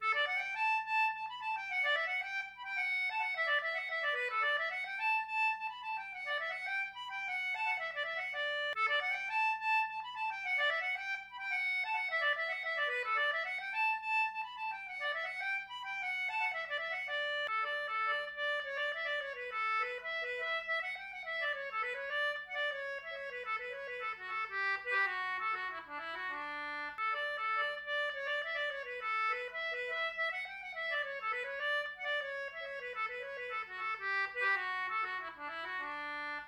Celtic Button Box Playlist Samples
REELS
English concertina